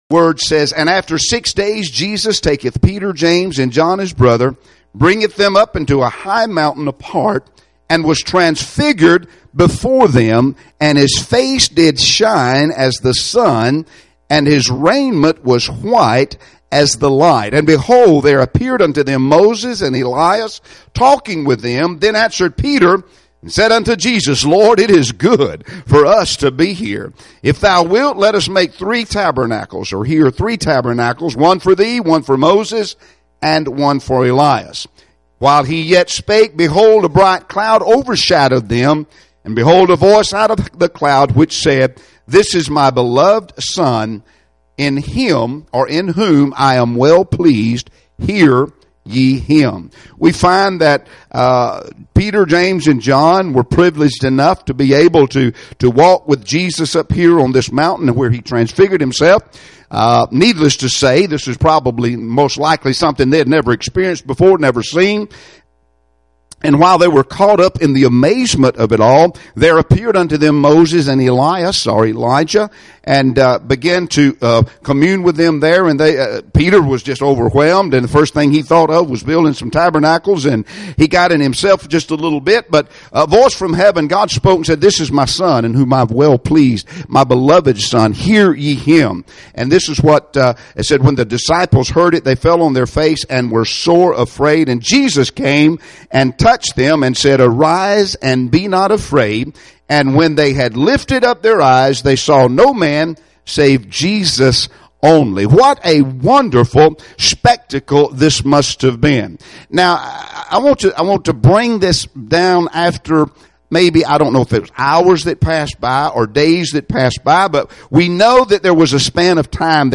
Matthew 17 Service Type: Wednesday Evening Services Topics